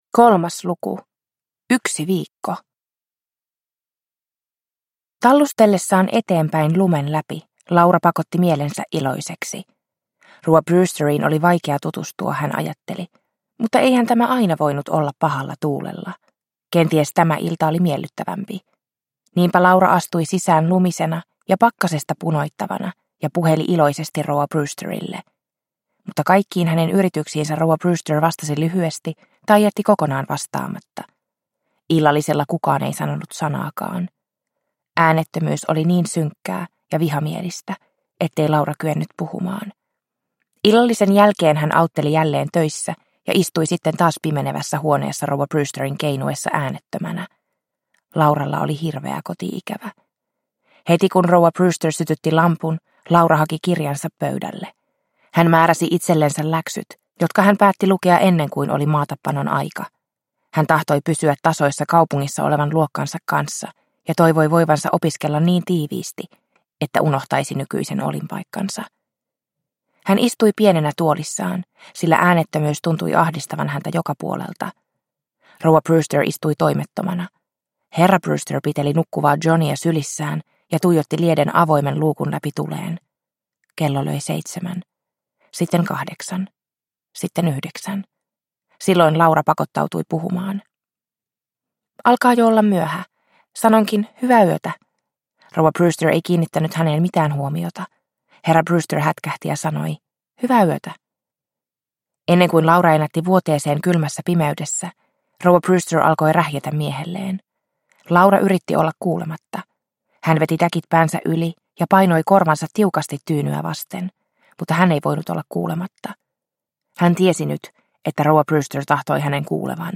Onnen kultaiset vuodet – Ljudbok – Laddas ner